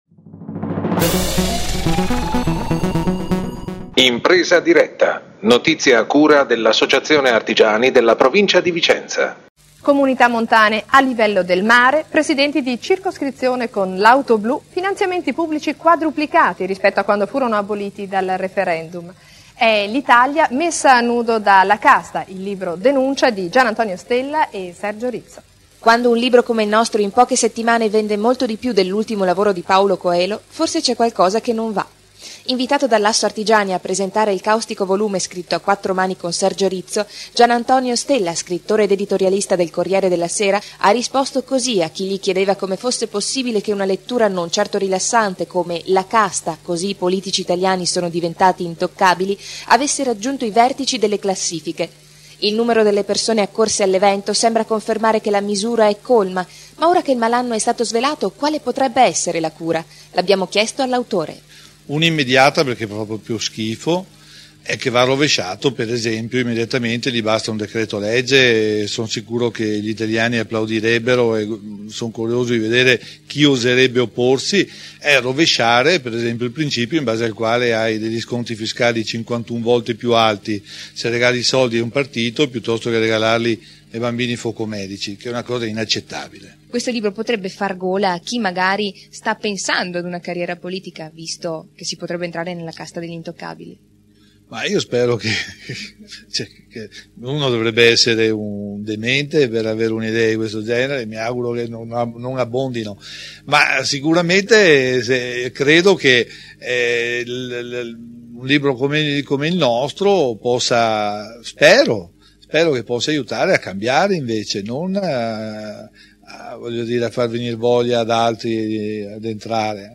L’editorialista del Corriere della Sera GIANANTONIO STELLA alla presentazione del libro “La Casta”, scritto assieme al collega Sergio Rizzo. https